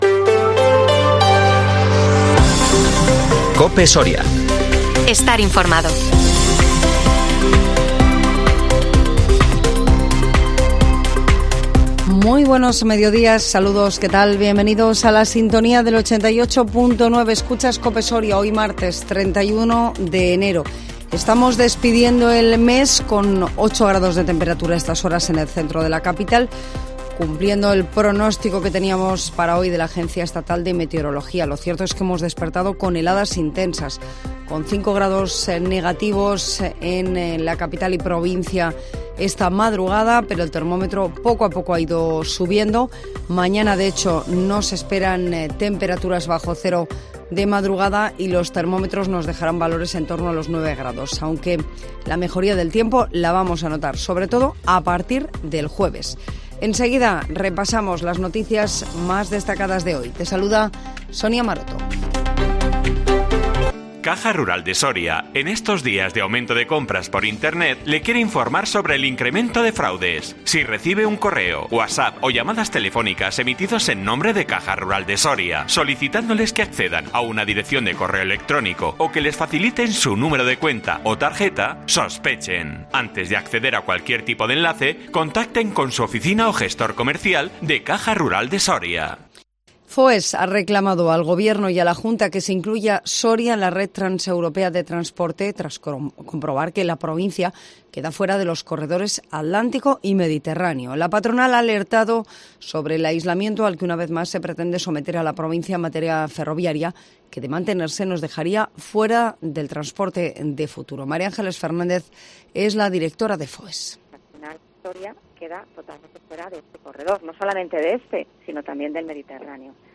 INFORMATIVO MEDIODÍA COPE SORIA 31 ENERO 2023